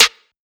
Hard Piano Snare.wav